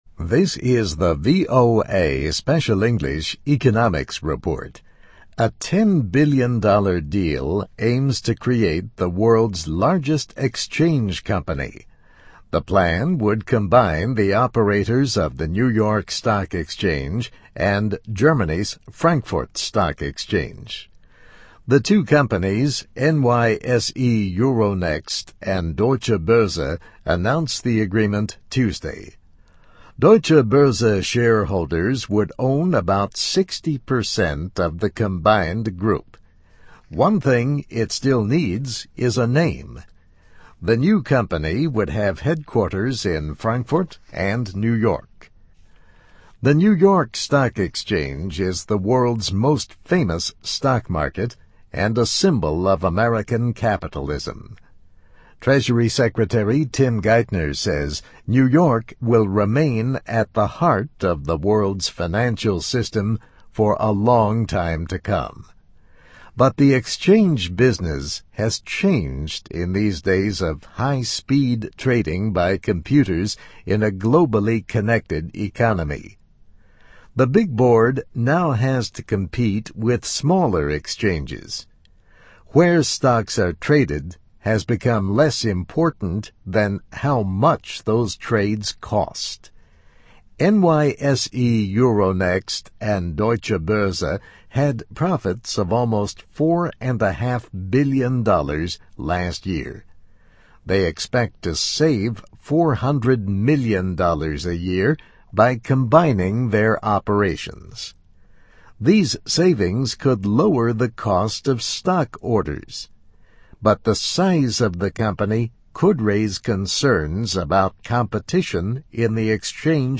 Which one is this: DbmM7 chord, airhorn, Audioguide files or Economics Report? Economics Report